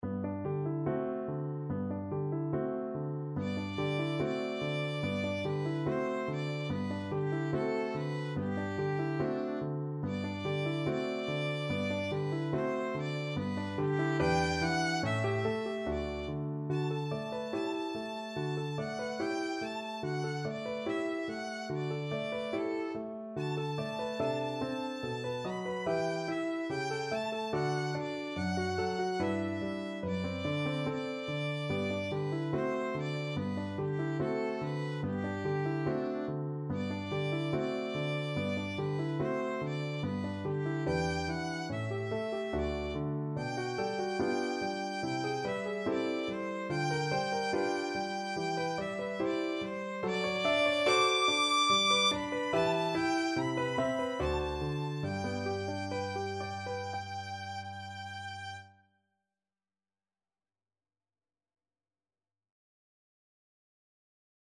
Classical Fauré, Gabriel Berceuse from Dolly Suite Op 56 no1 Violin version
Violin
Allegretto moderato =72
2/4 (View more 2/4 Music)
G major (Sounding Pitch) (View more G major Music for Violin )
Classical (View more Classical Violin Music)